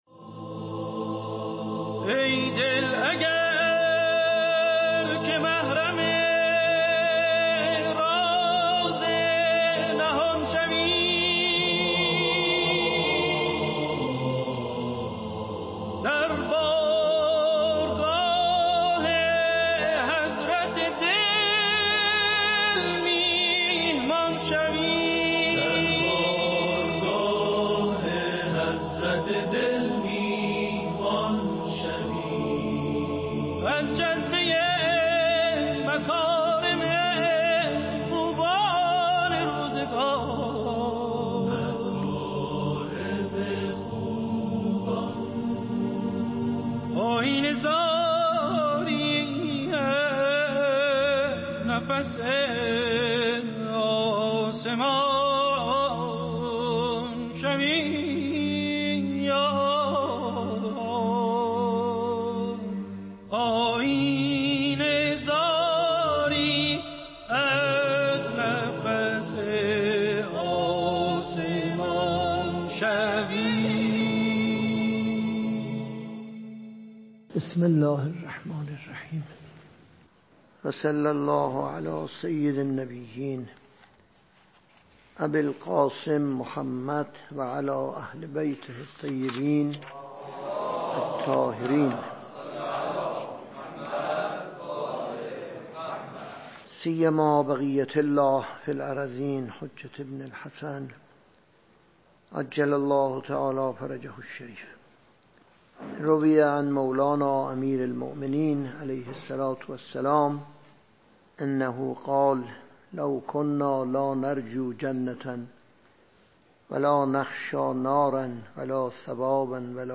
درس اخلاق | چگونه تربیت و ادب، استعداد اخلاقی انسان را شکوفا می کند؟